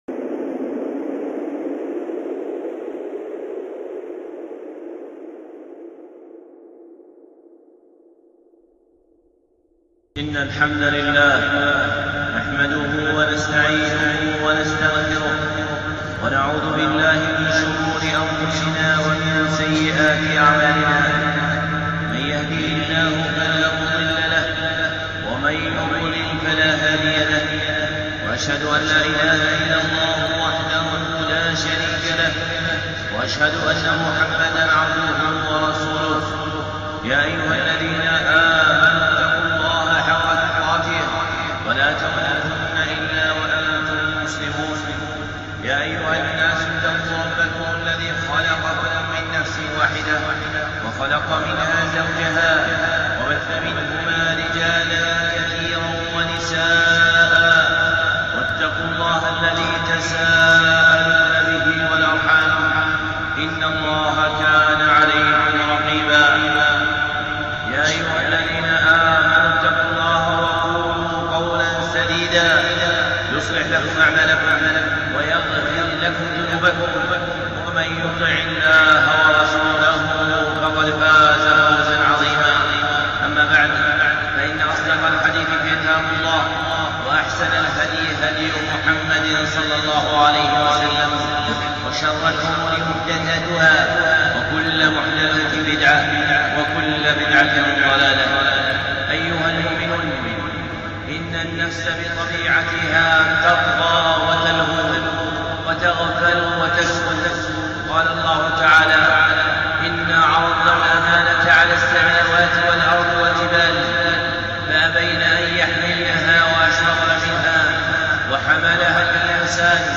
الخطب المنبرية